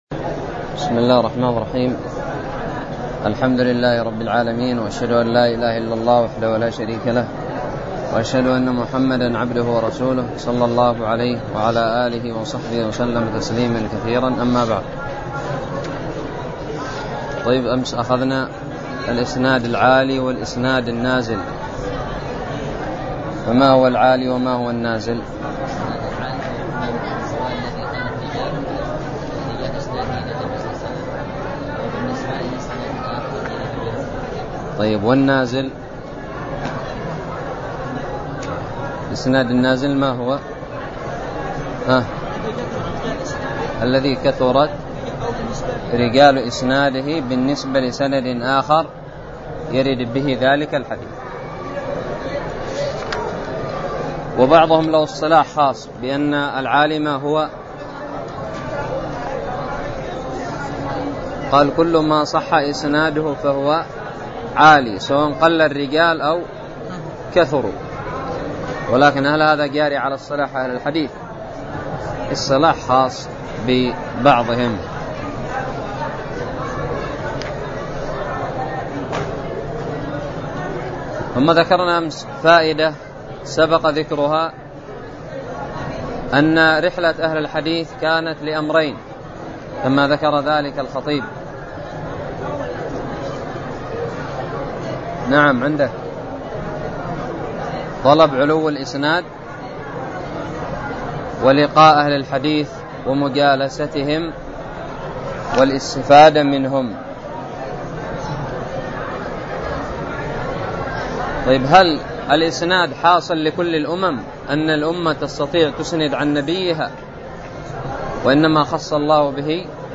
الدرس الثاني والأربعون من شرح كتاب الباعث الحثيث
ألقيت بدار الحديث السلفية للعلوم الشرعية بالضالع